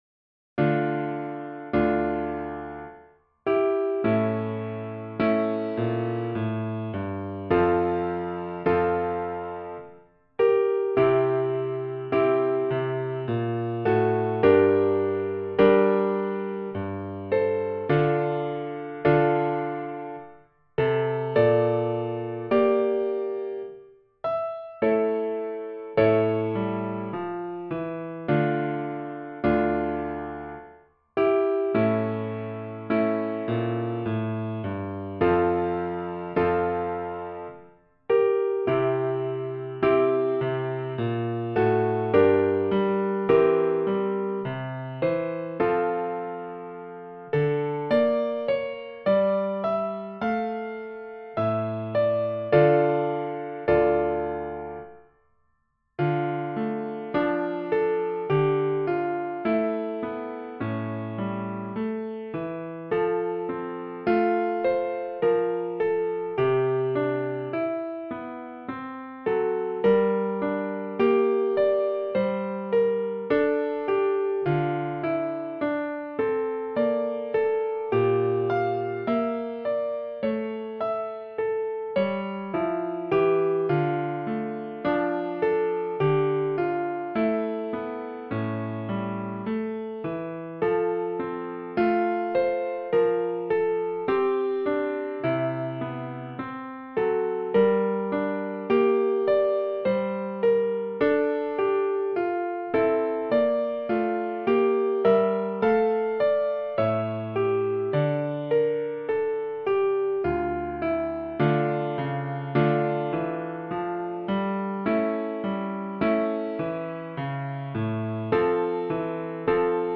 Piano Sheet Music